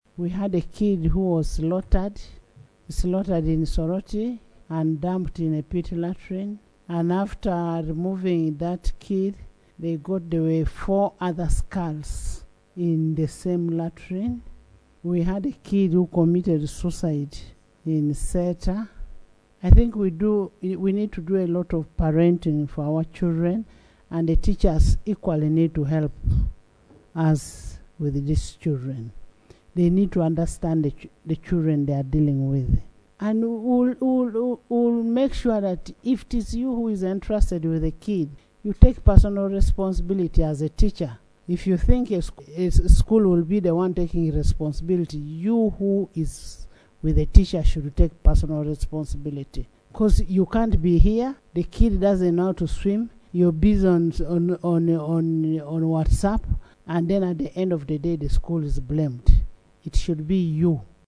In her communication as she presided over the House on Tuesday, 25 February 2025, Speaker Among raised concerns following reports about the drowning of a pupil of Victorious School in Kampala and the hacking to death of a Primary One pupil at Jozan Nursery and Primary School in Soroti.